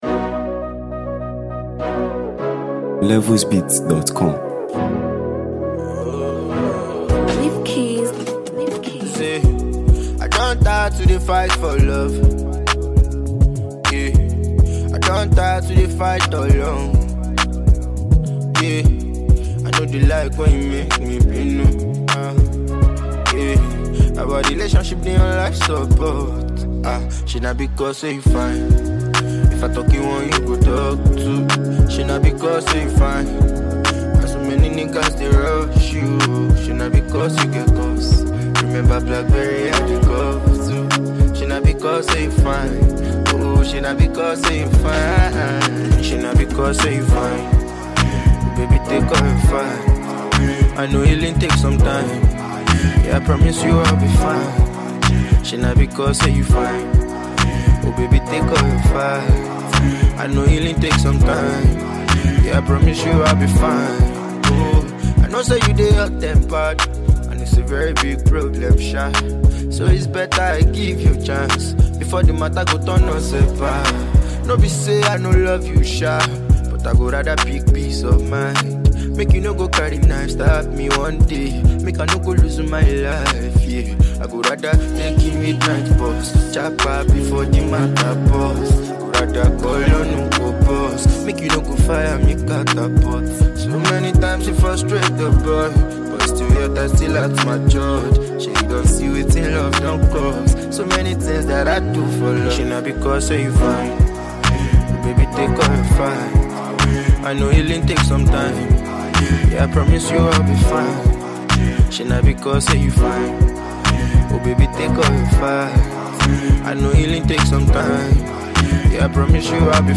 If you’re a fan of quality Nigerian Afrobeat tunes